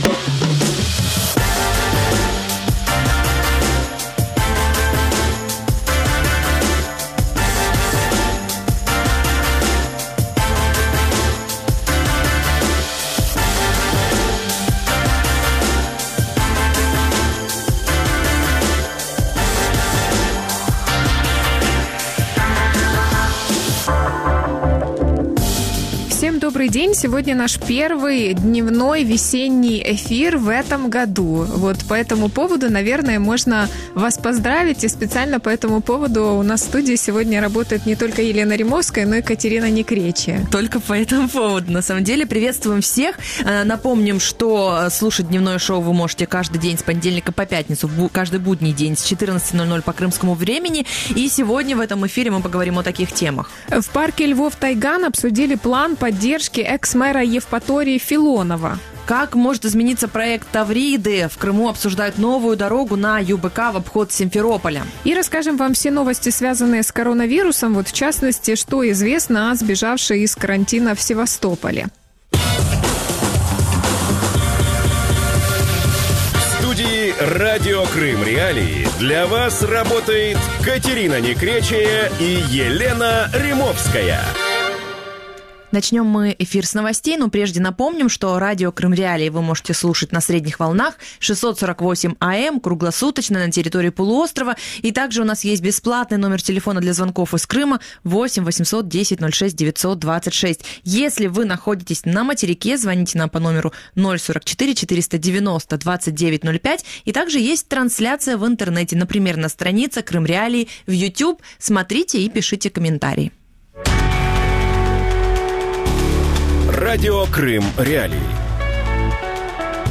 Трасса «Таврида» дотянется до Ялты? | Дневное ток-шоу